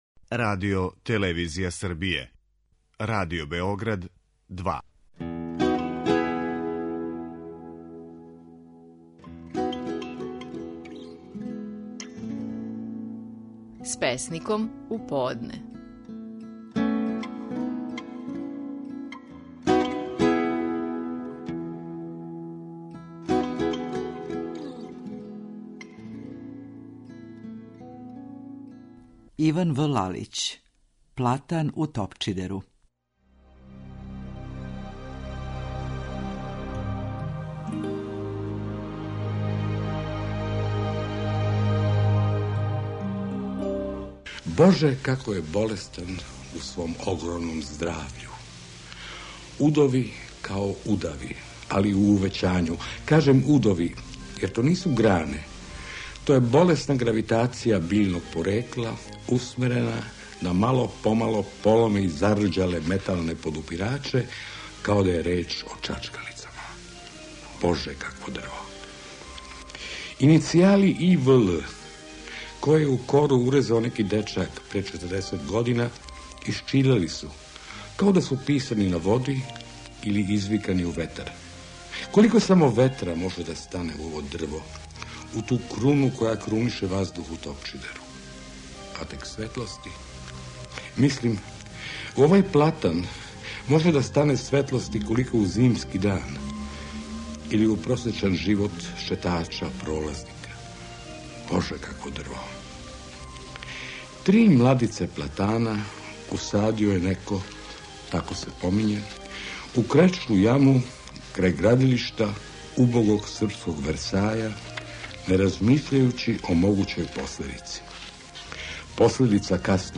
Наши најпознатији песници говоре своје стихове
Иван В. Лалић говори своју песму „Платан у Топчидеру".